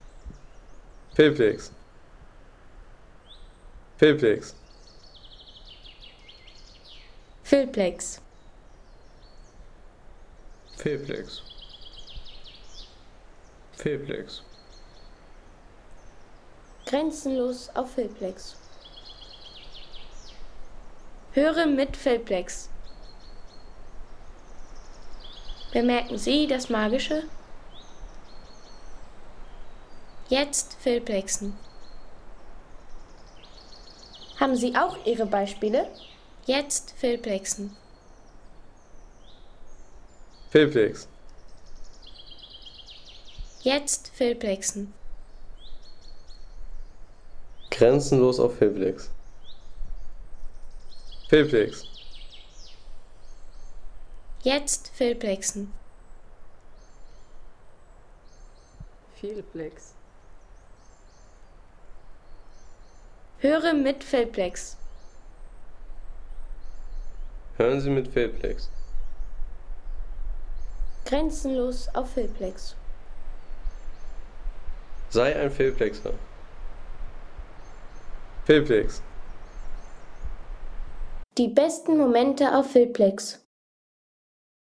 Waldgeräusche bei starkem Wind.